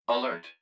042_Alert2.wav